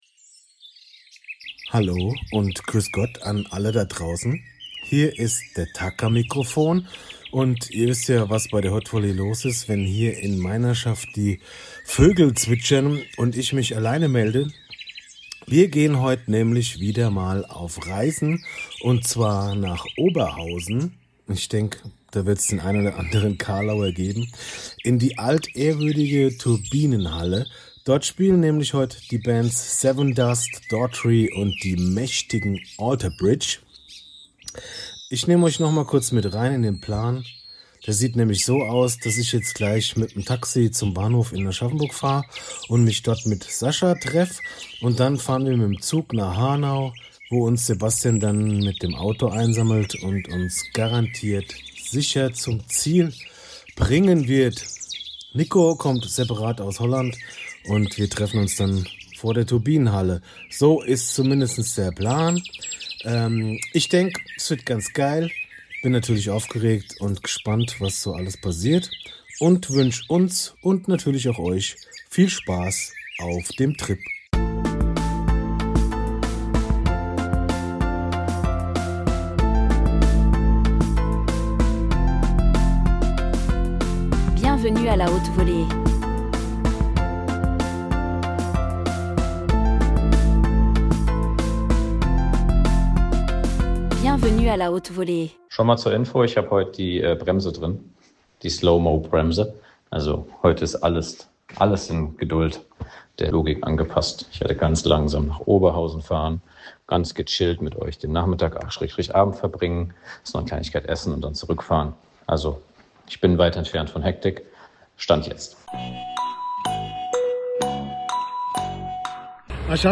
Wenn im Intro die Vögel zwitschern, wissen treue Hörer: Heute wird Unsinn gemacht. Dieses Mal führt uns der Weg nach Oberhausen, genauer gesagt in die Turbinenhalle, wo Alter Bridge, Sevendust und Daughtry auf dem Programm stehen.